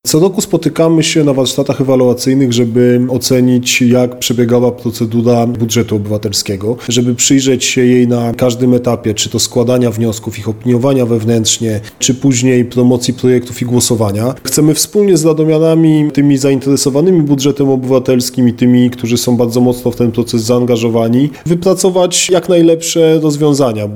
Wnioski wypracowane w trakcie warsztatów zostaną wykorzystane w pracach nad nowymi zapisami w uchwale Rady Miejskiej w Radomiu w sprawie wymagań, jakie powinien spełniać projekt BOM na 2023 rok. Mówi Mateusz Tyczyński, wiceprezydent Radomia.